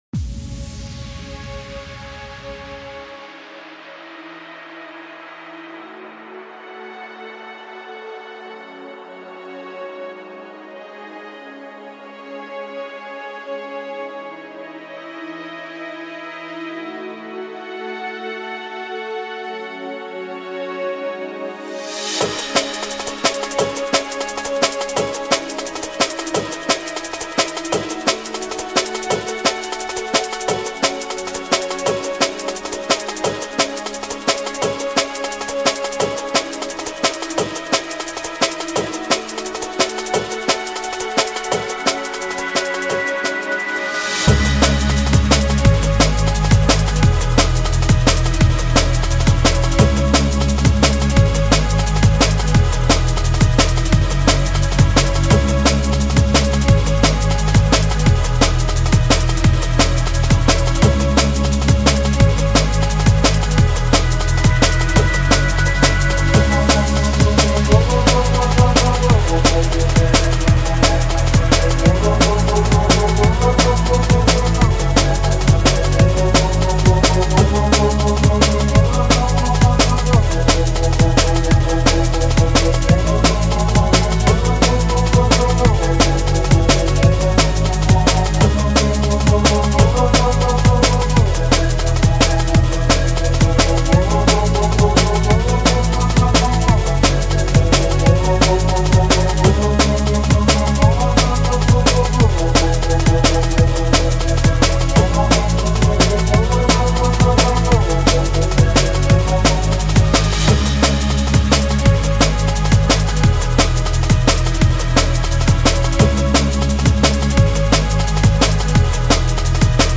genre:dnb